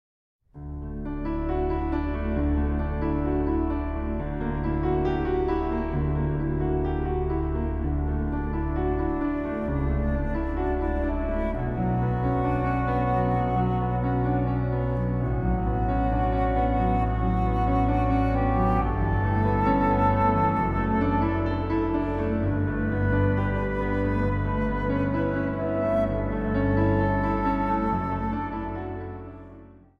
Instrumentale bewerkingen over Israël en Jeruzalem
Instrumentaal | Dwarsfluit
Instrumentaal | Hobo
Instrumentaal | Synthesizer
Instrumentaal | Viool